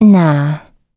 Gremory-Vox-Deny.wav